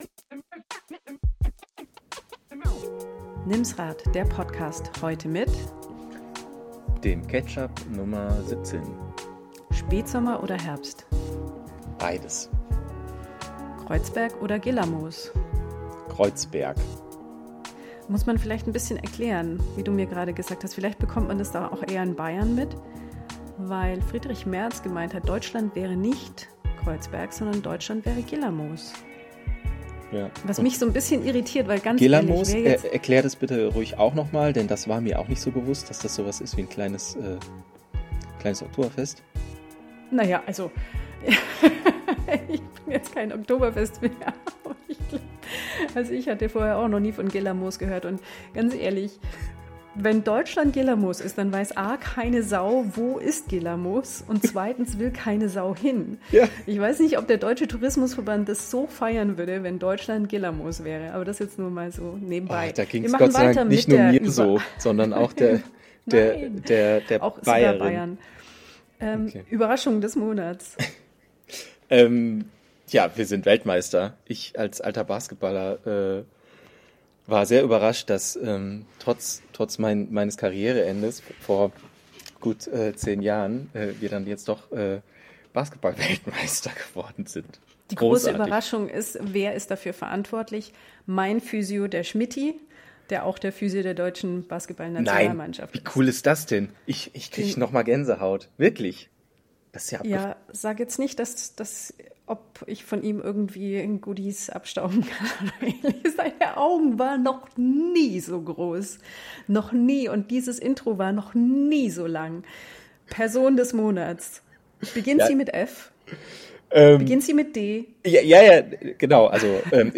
Als kleines Extra haben wir noch Stimmen von u. a. Evoc und Kettler.